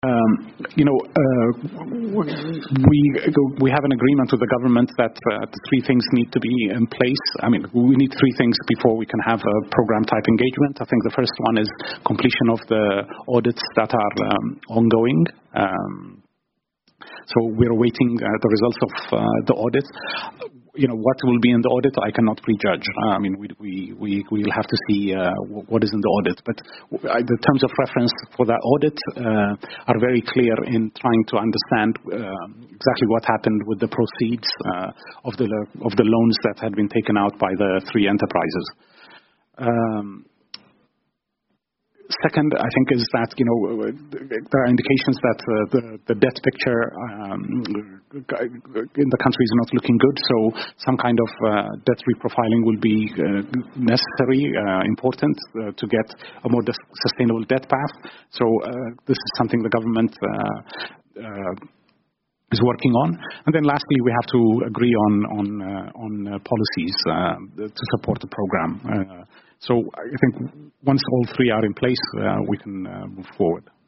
Falando num conferência de imprensa em Washington D.C.